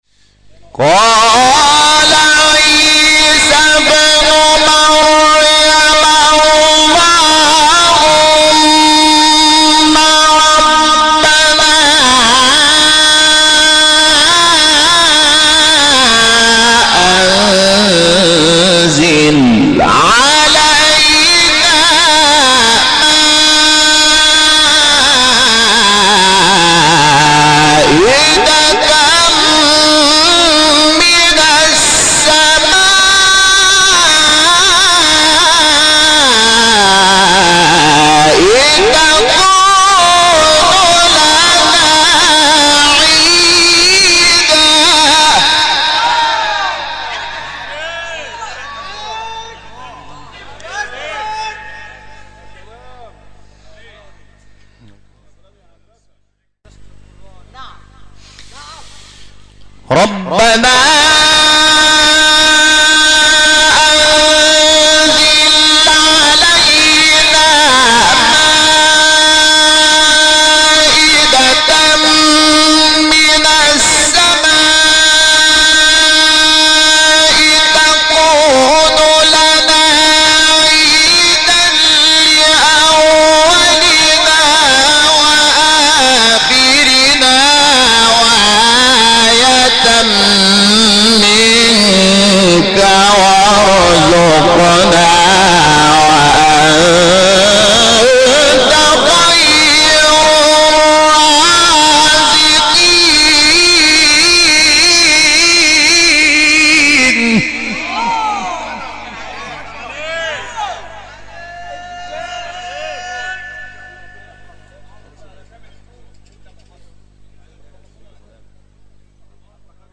سوره: مائده آیه: 114 استاد: محمود شحات مقام: عجم قبلی بعدی